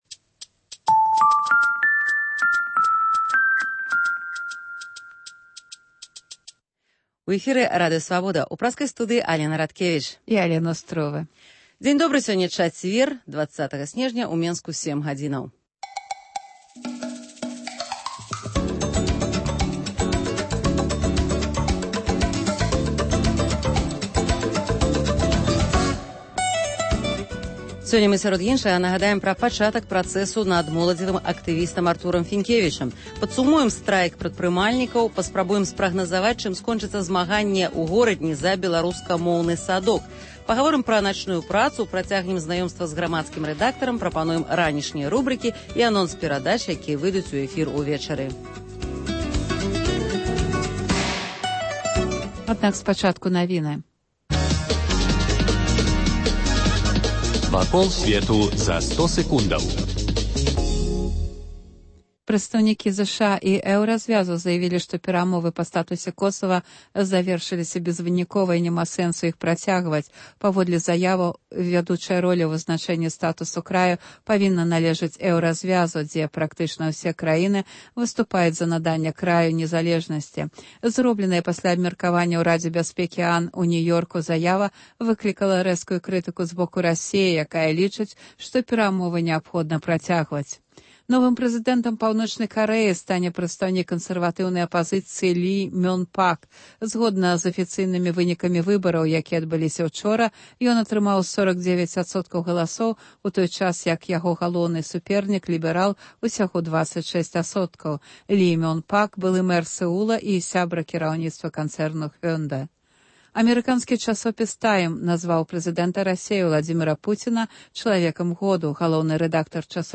Ранішні жывы эфір
Бліц-аналіз